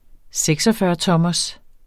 Udtale [ ˈsεgsʌfɶːʌˌtʌmʌs ]